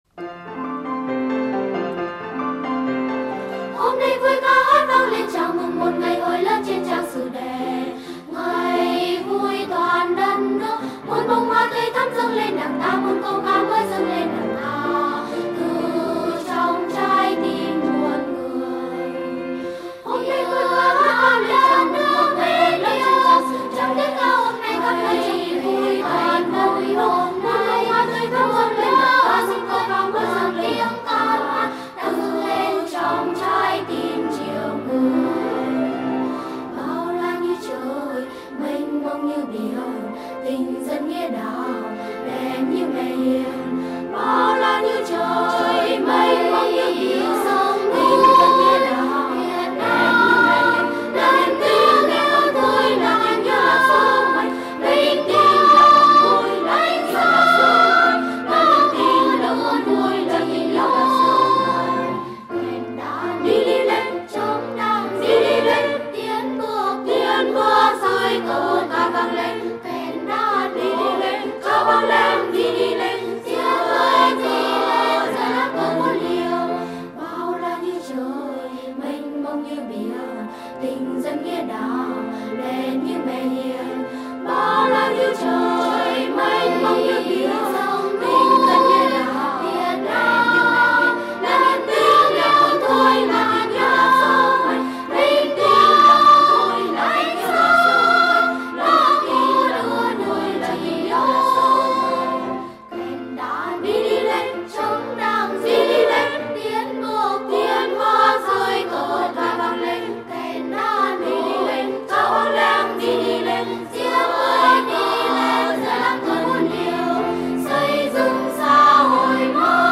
Coro, épico, nuevo typo de música vocal